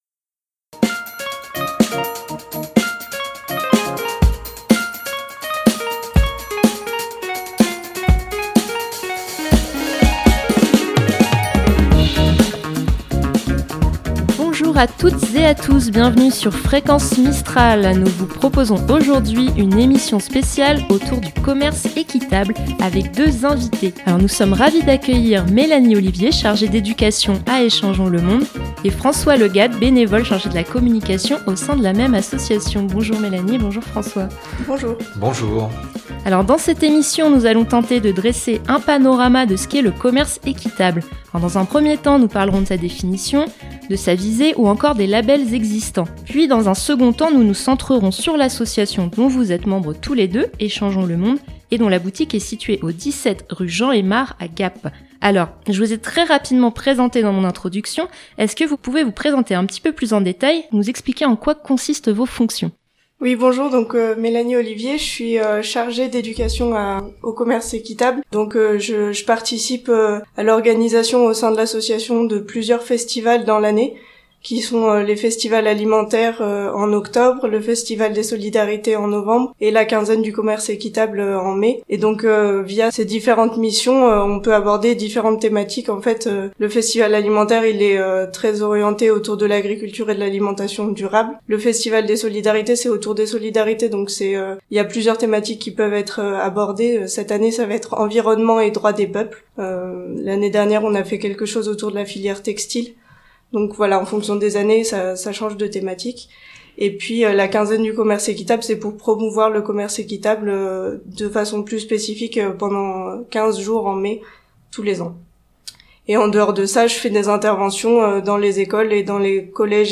Pour nous éclairer sur le commerce équitable, les principes qui le régissent, ou encore sur la myriade de labels qui existent, nous recevons deux invités :